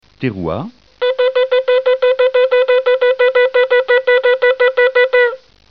Le Choffar
Terouah", dont on sonne neuf fois au minimum, suivi d'une fusion de ces deux sons
La Terouah est composée de neuf sons brefs au minimum (on peut faire plus....)